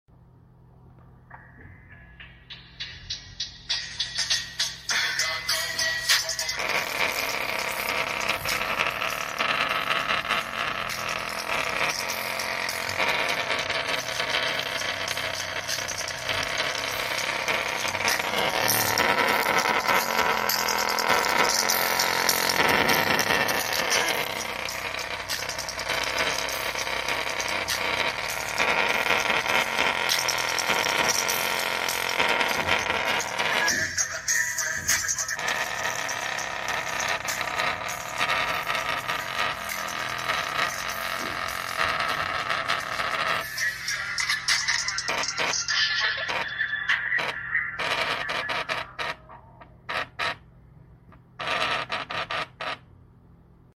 Anker sound core nano bass sound effects free download